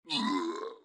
PLAY Sronghold_ph_ally_death_01